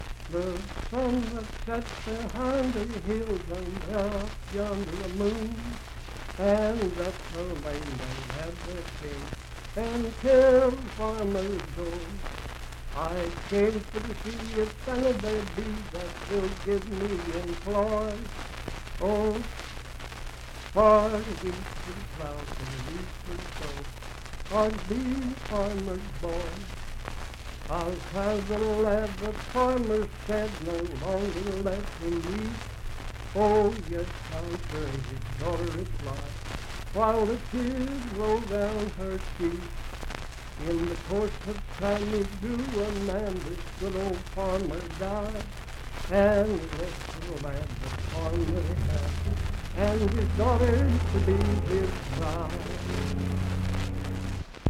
Unaccompanied vocal music
Verse-refrain 4(4).
Voice (sung)
Grant County (W. Va.)